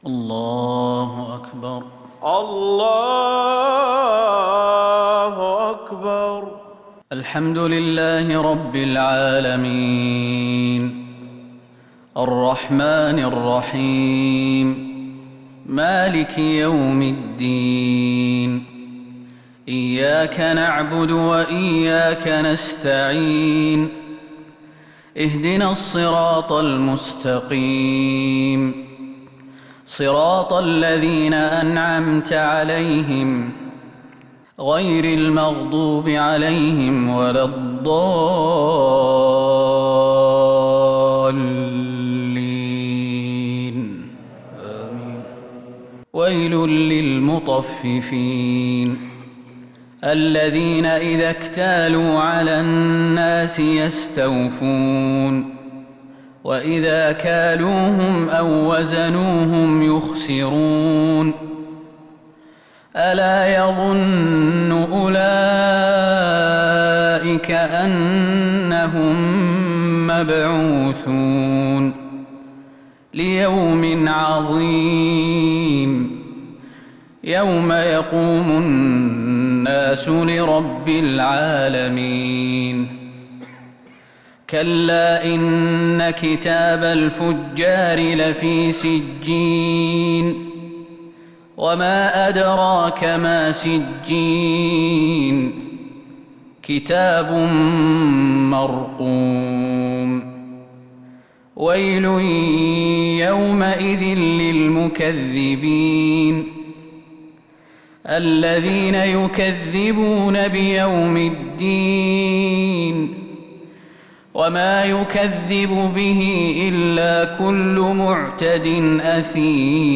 صلاة الفجر للقارئ خالد المهنا 4 ربيع الأول 1442 هـ
تِلَاوَات الْحَرَمَيْن .